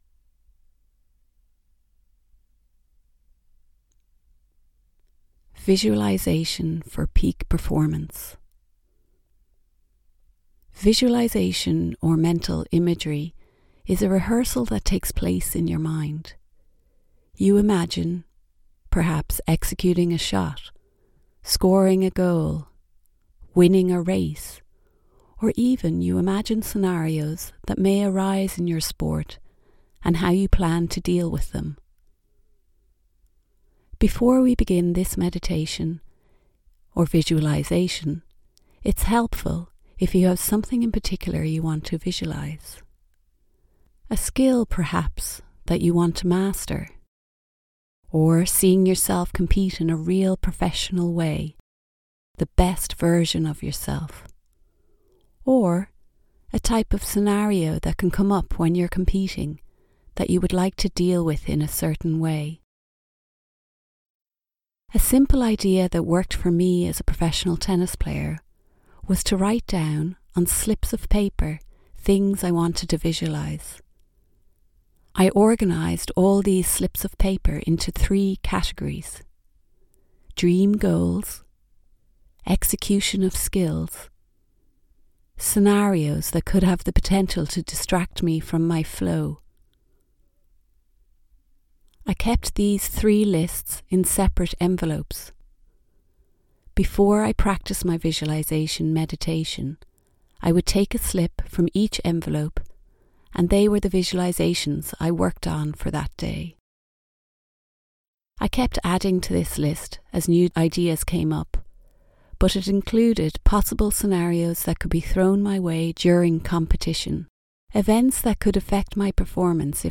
The Be Present - Compete course consists of guided meditations, for competitive athletes and performers, helping them access their flow state on a more frequent basis, leading to improved results and more thorough enjoyment of their competitive endeavours.